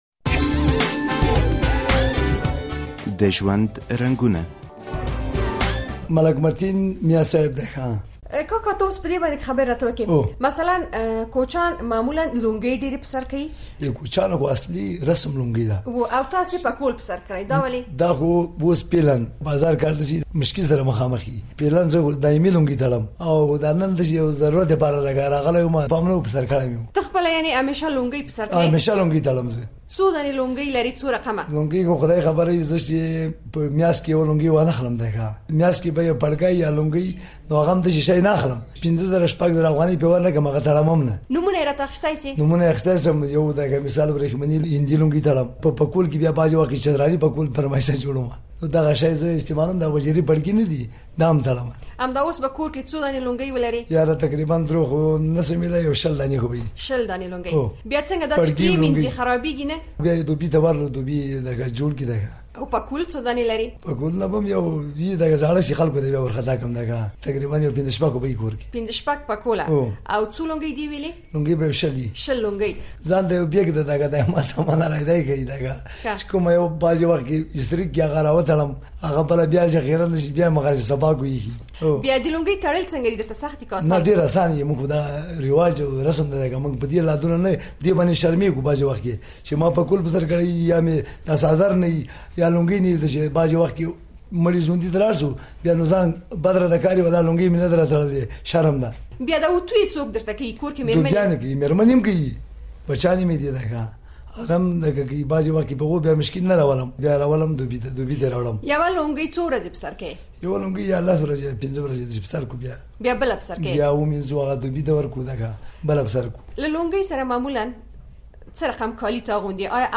د ژوند په رنګونو کې په دې لړۍ کې دا ځل له داسې یوه کس سره غږیږو چې د لونګې لرغونې تاریخ رابیانوي: